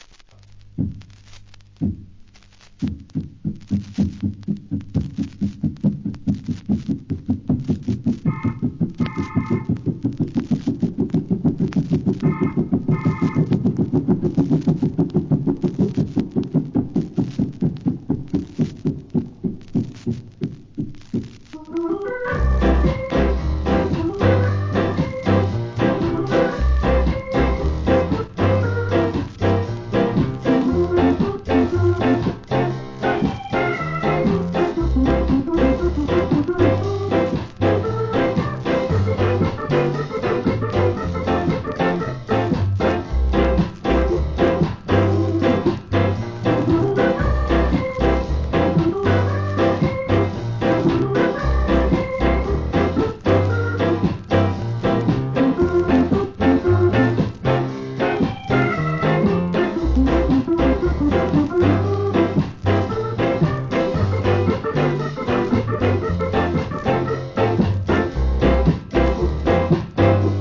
1. REGGAE
レアSKA!! 再発